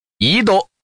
Index of /hunan_master/update/12815/res/sfx/changsha_man/